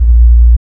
5408R BASS.wav